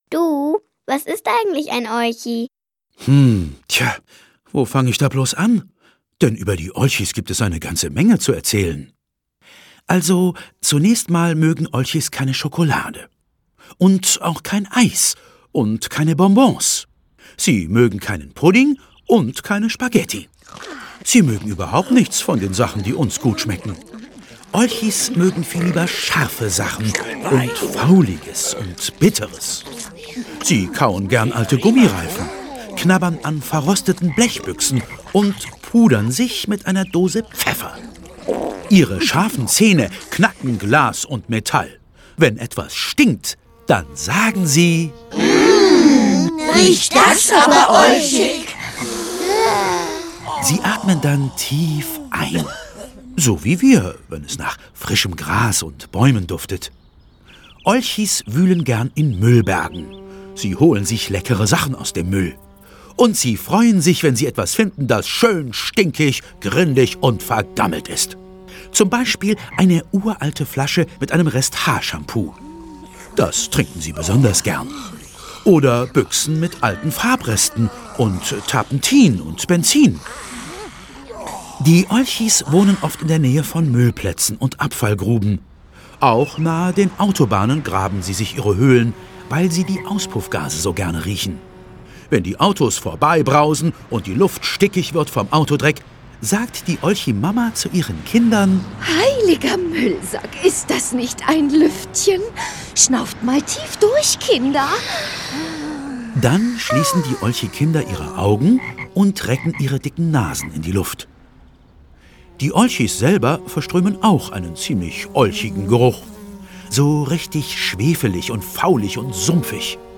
Ravensburger Die Olchis sind da ✔ tiptoi® Hörbuch ab 5 Jahren ✔ Jetzt online herunterladen!